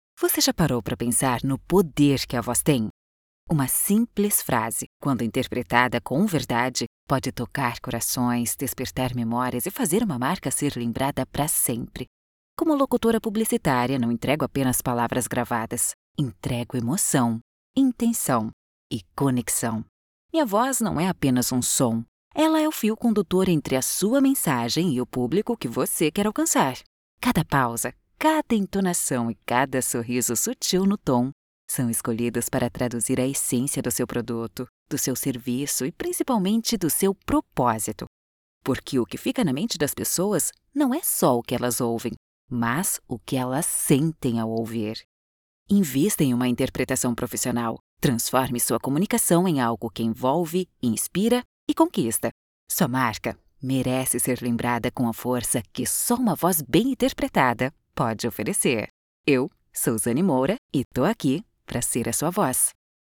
Spot Comercial
Animada